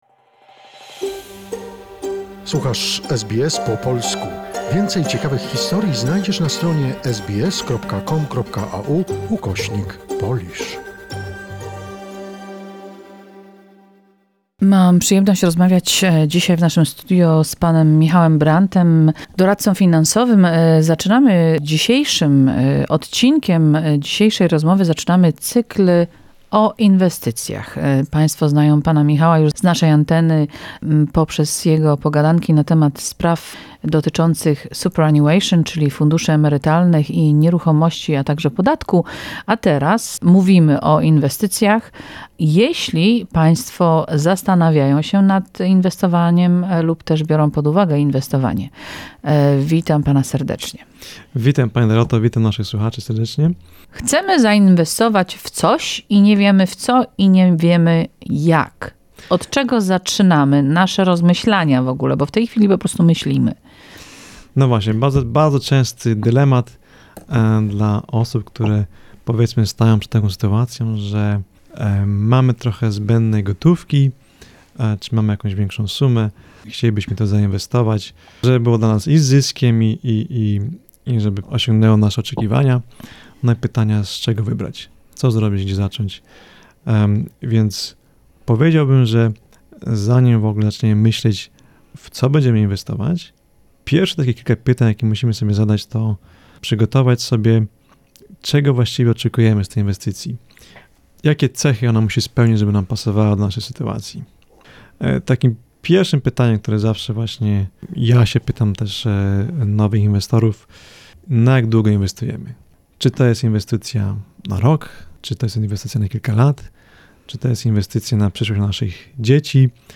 This is the first part of the conversation with an expert.